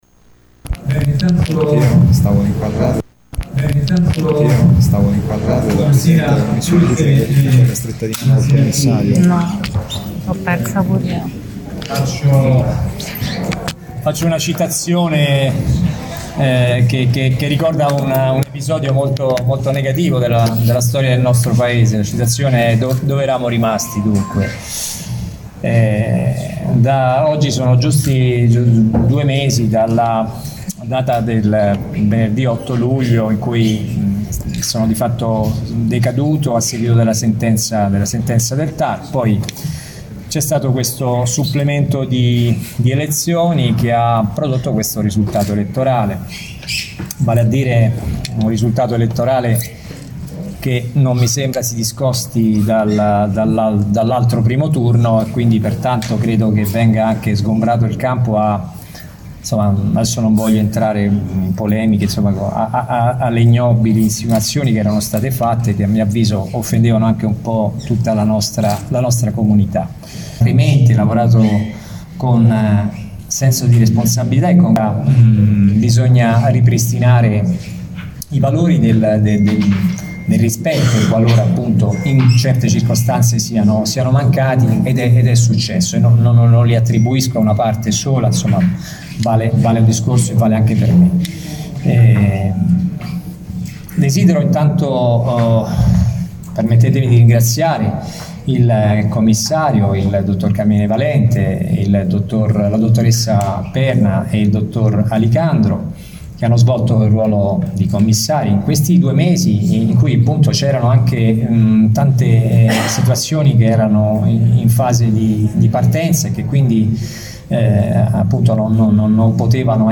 Damiano Coletta torna sindaco di Latina: la proclamazione e il discorso in aula consiliare. AUDIO INTEGRALE
IL DISCORSO
COLETTA-DISCORSO-1.mp3